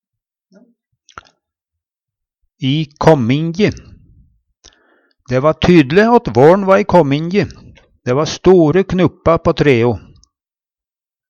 i kåmmingje - Numedalsmål (en-US)
DIALEKTORD PÅ NORMERT NORSK i kåmmingje i kjømda, i starten Eksempel på bruk Dæ va tydle åt vår'n va i kåmmingje.